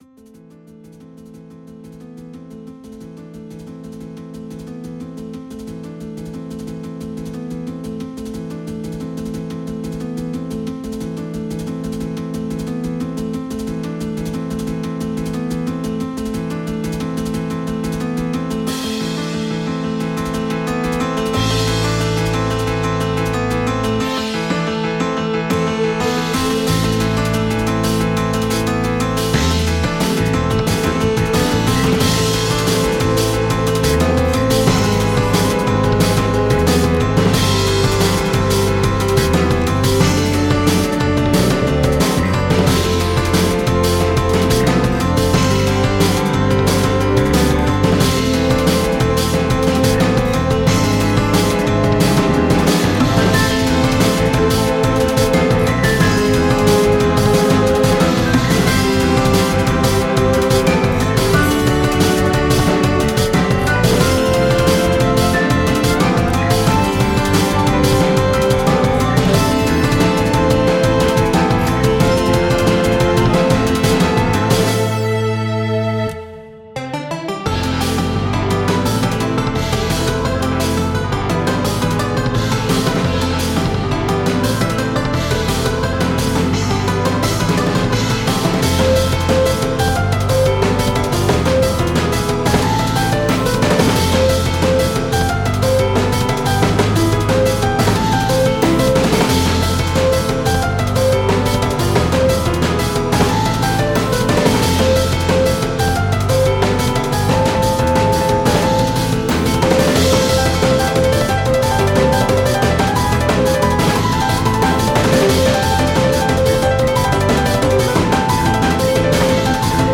Piano; guitarra acústica; leads
Batería; bajo; detalles
Piano; violín; detalles